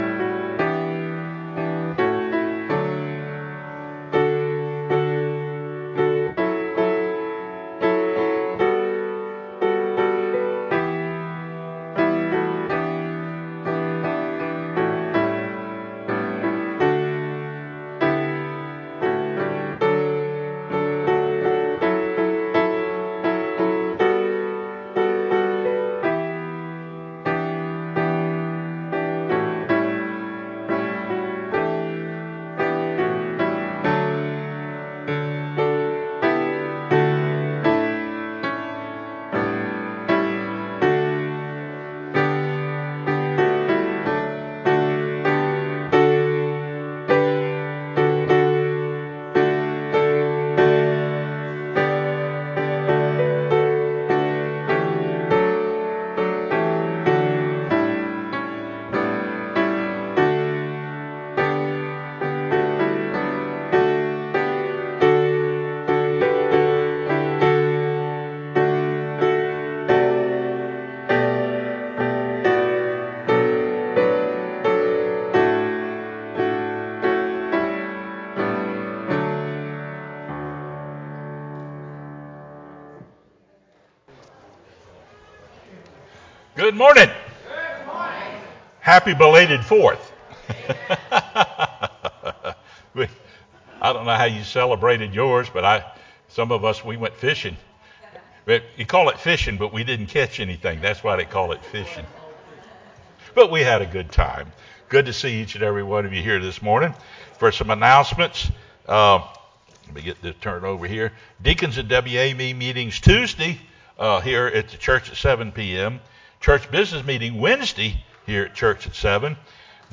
sermonJuly06-CD.mp3